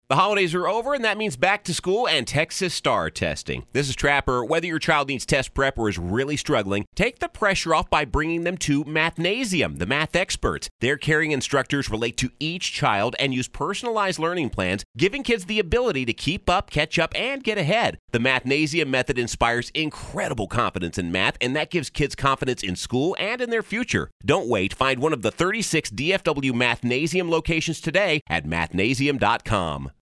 30-second audio spot.mp3